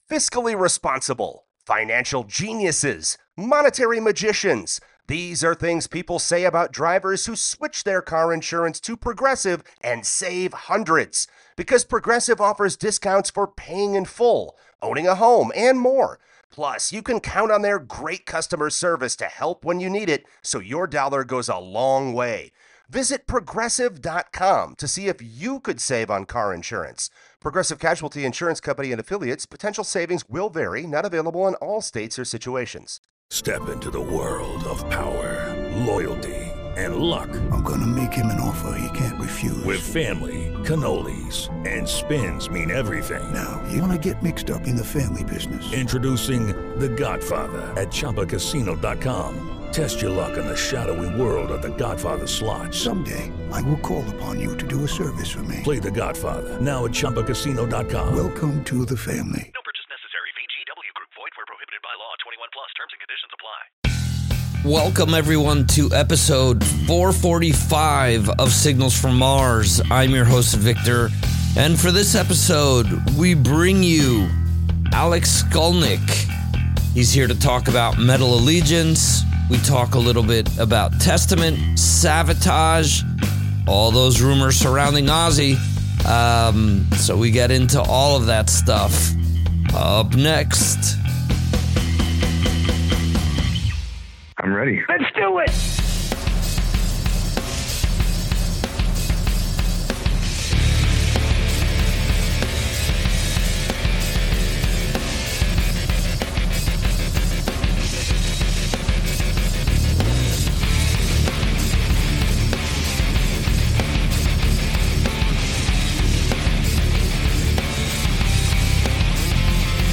a wide-ranging conversation